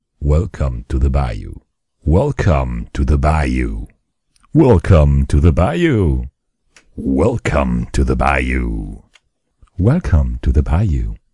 啁啾声
标签： 语音 言语 啁啾 声乐 请求
声道立体声